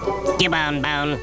A sound clip of Cubone.
cubone.wav